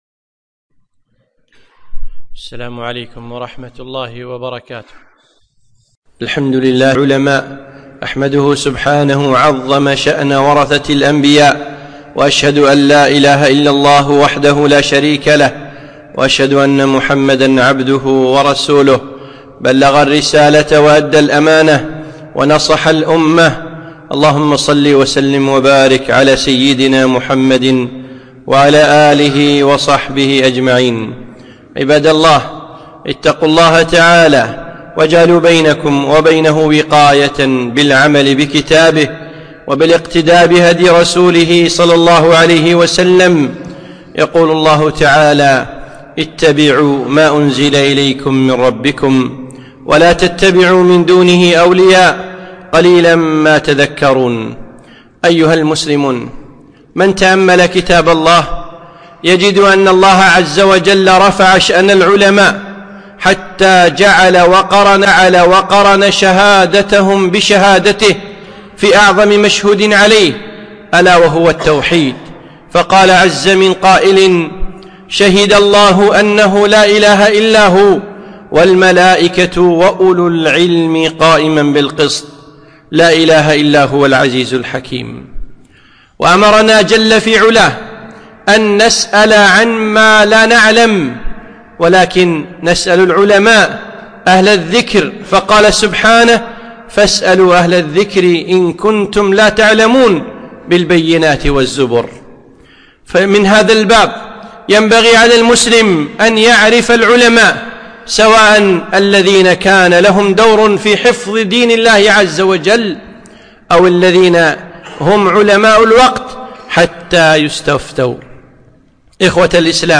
خطبة - الإمام مسلم